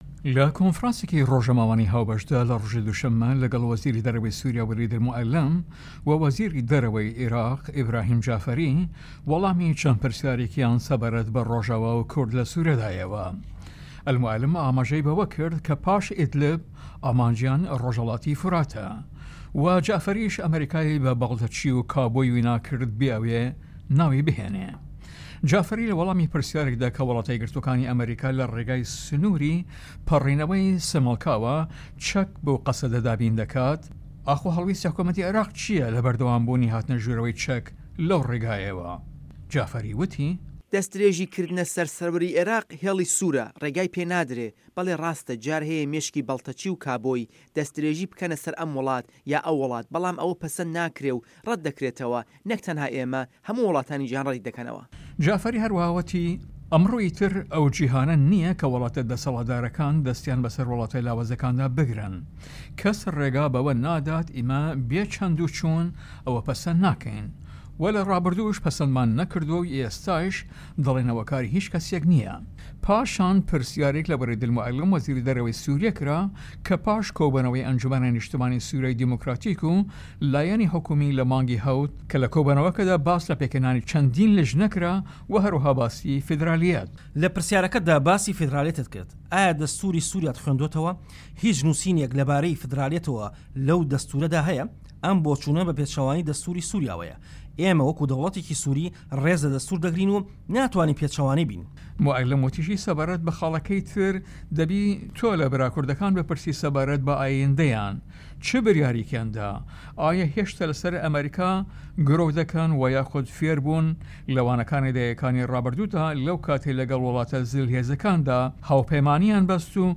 کۆنفرانسی رۆژنامەوانی وەزیرانی دەرەوەی سوریا و عێراق